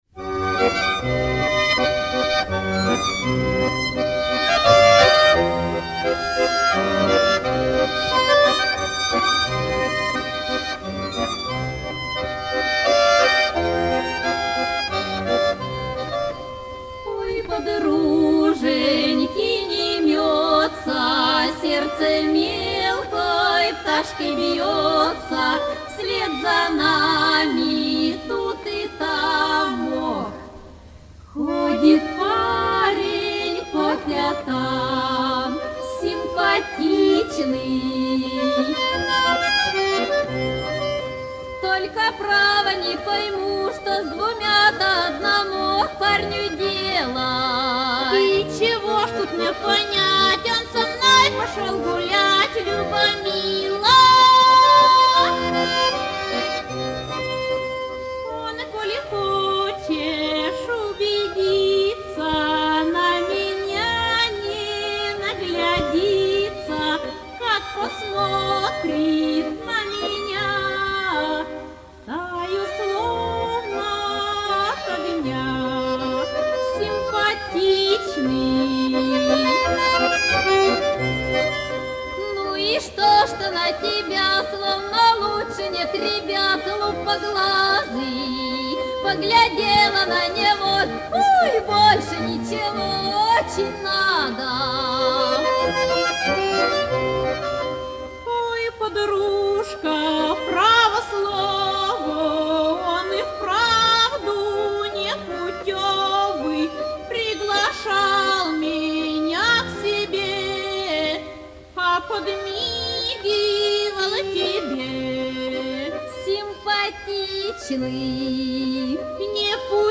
Бытовая зарисовочная песня-спор.
гармоника